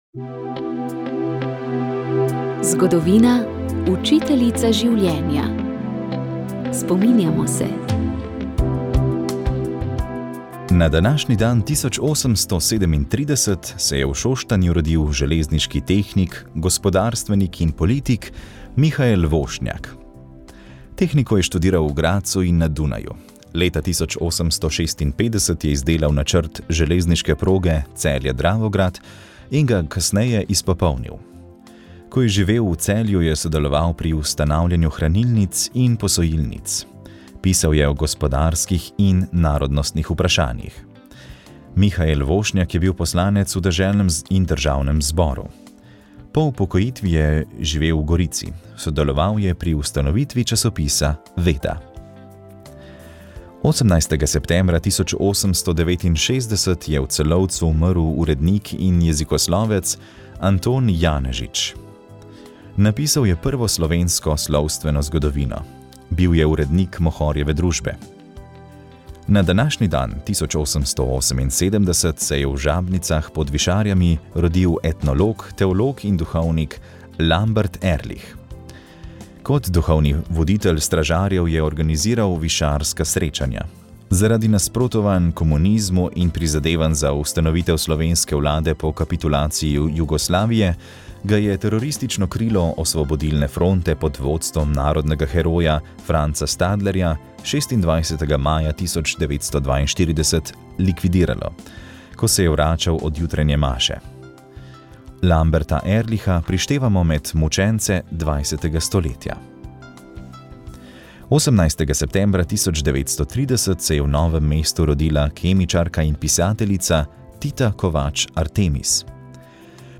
Informativni prispevki
Kaj to pomeni za arbitražno razsodbo, smo vprašali profesorja mednarodnega pomorskega prava dr. Marka Pavliho. Dejal je, da je žal takšno odločitev sodišča pričakoval, vseeno pa dodal, da upanje umre zadnje.